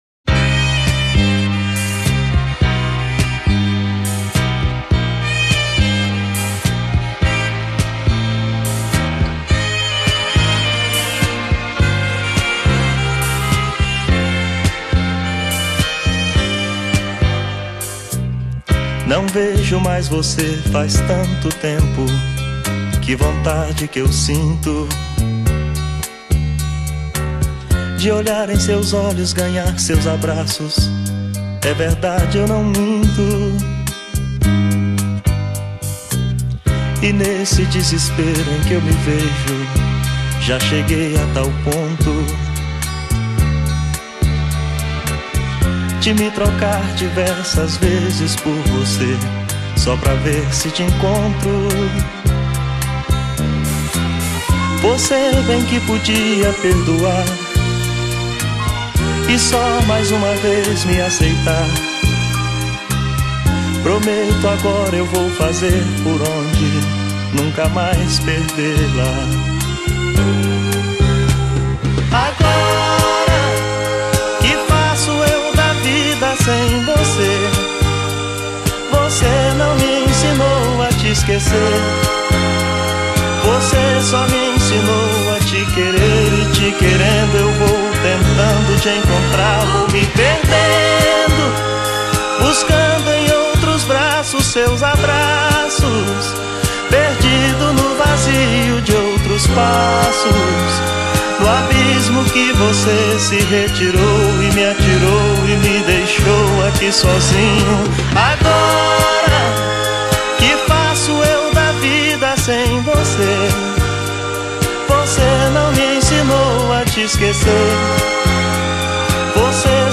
2025-02-23 01:20:36 Gênero: MPB Views